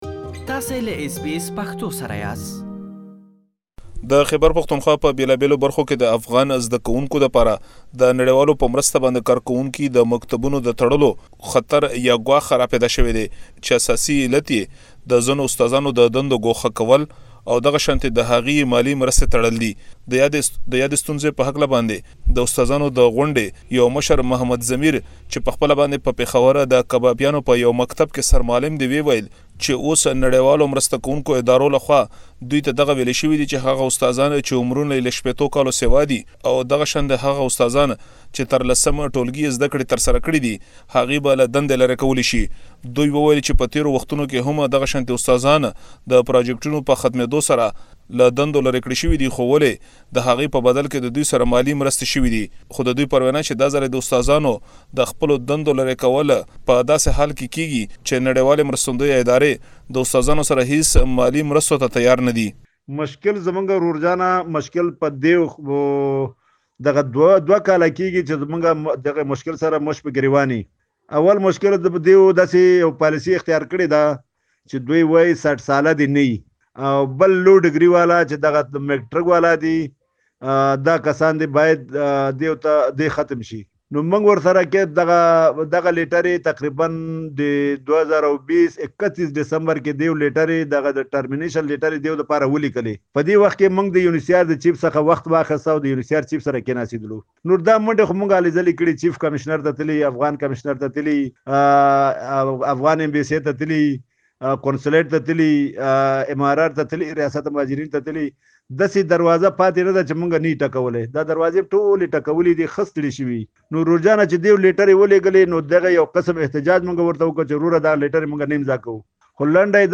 تاسو ته مو پاکستان کې د افغان کډوالو ښوونځيو مشرانو خبرې په رپوټ کې ځای کړي چې دا ټول دلته اوريدلی شئ.